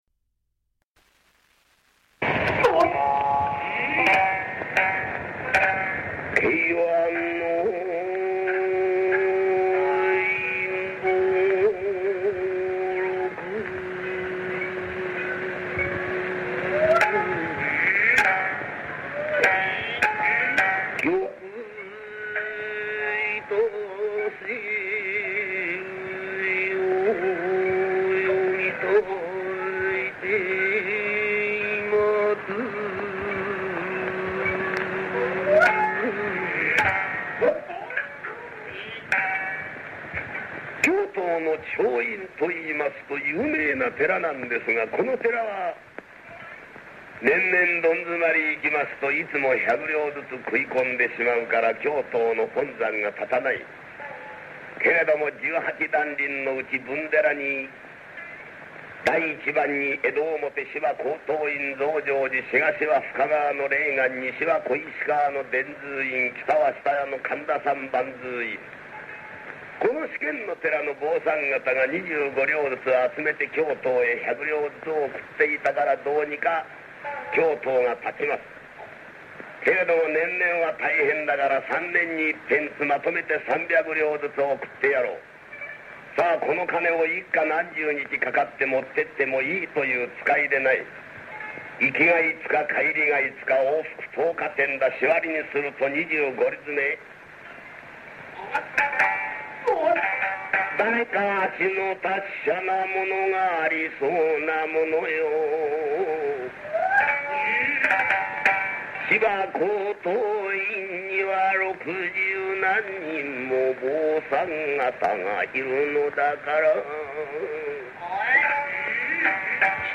初代木村重松　慶安太平記　怪僧善逹道中附　M-1（15:28）
重松の芸は雲右衛門とは違い豪快ではないが、何とも言えない味がある節で、啖呵はべらんめい口調で侍も、殿様も、ヤクザ者も同じ調子でやりながらも写実的で、非常に愉快な浪花節である。